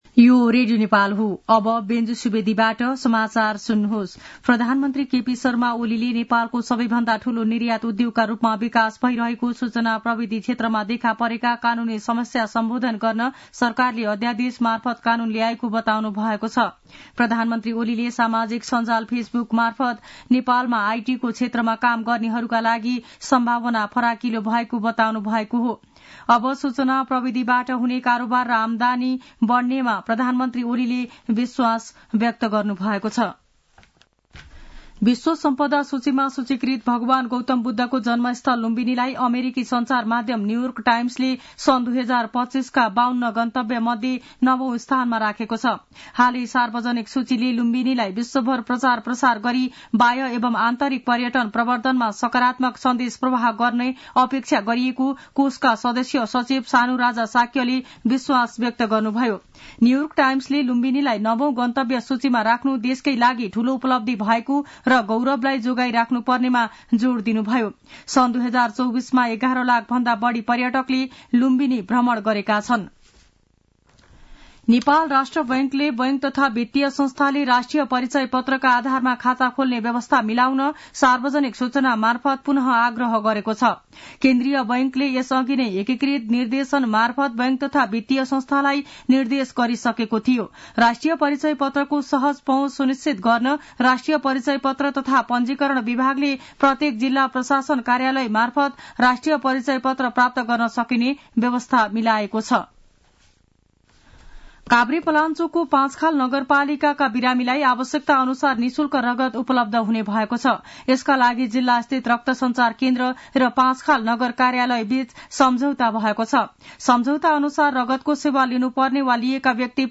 दिउँसो १ बजेको नेपाली समाचार : २९ पुष , २०८१
1-pm-news.mp3